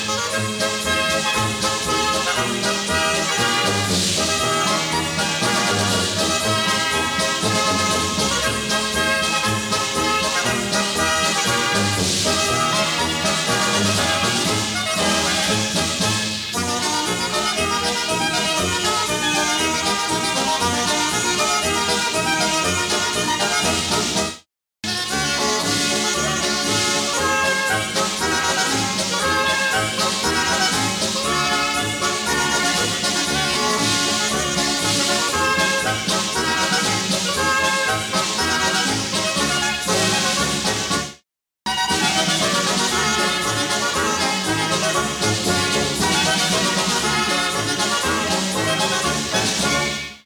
für Blaskapelle